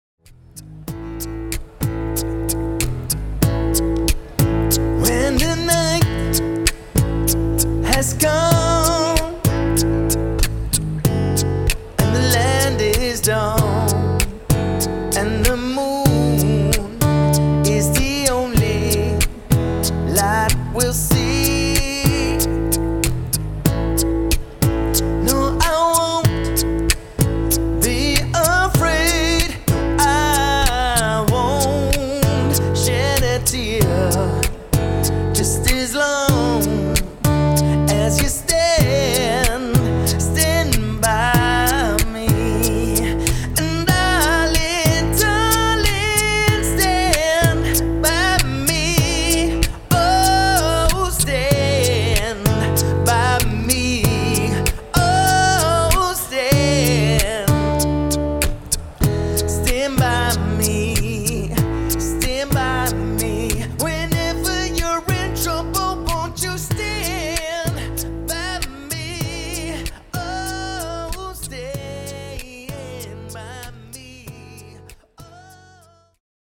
one man band